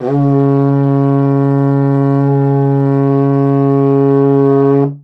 Index of /90_sSampleCDs/Best Service ProSamples vol.52 - World Instruments 2 [AKAI] 1CD/Partition C/TENOR HORN